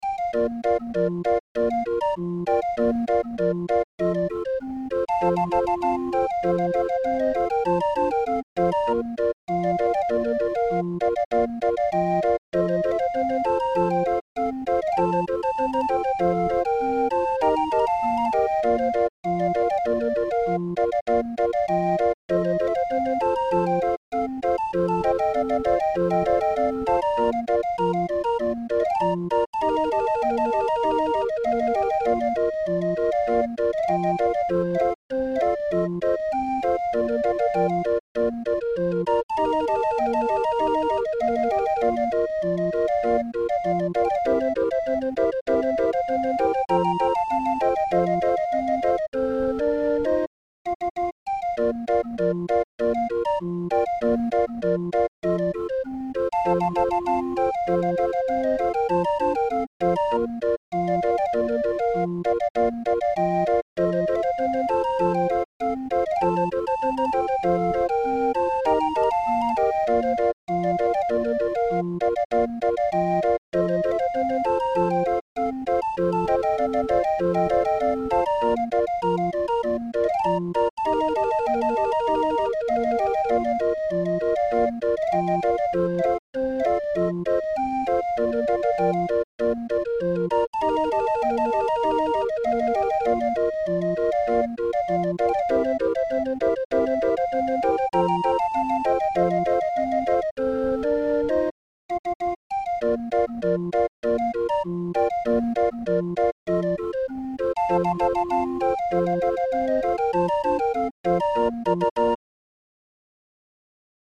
Musikrolle 20-er Raffin